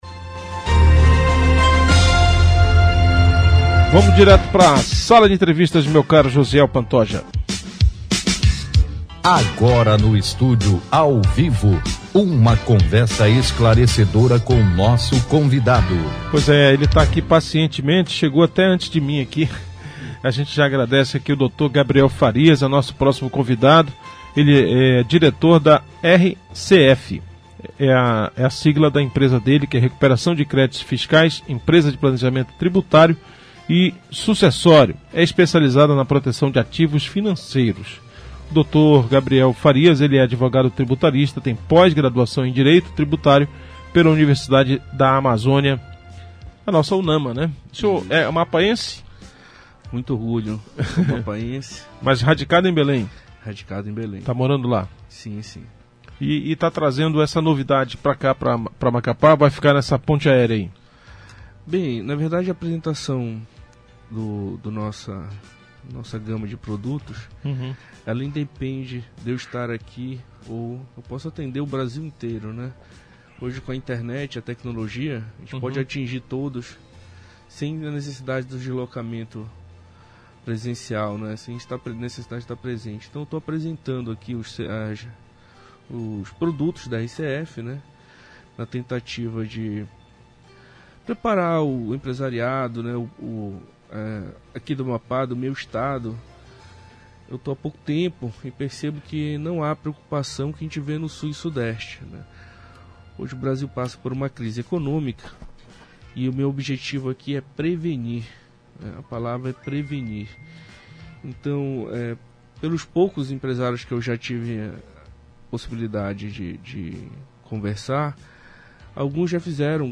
Ouça nossa entrevista na Radio Diário de Macapá – AP (90,9 FM)